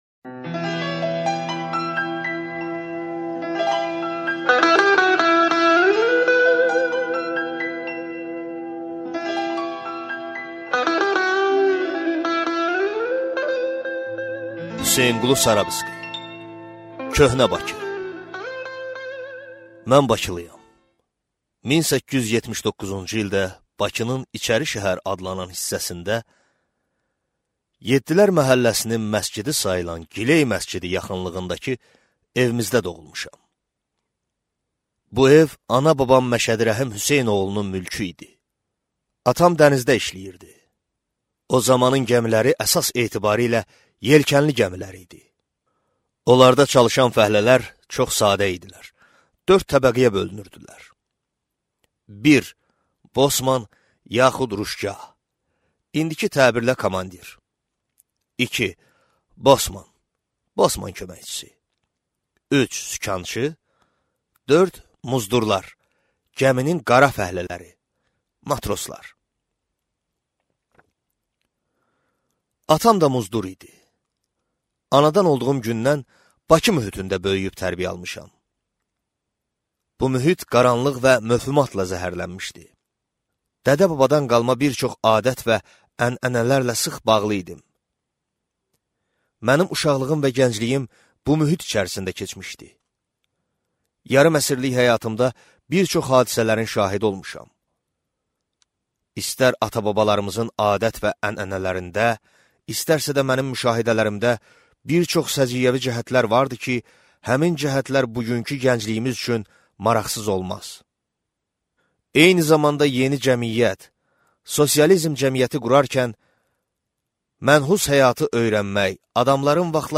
Аудиокнига Köhnə Bakı | Библиотека аудиокниг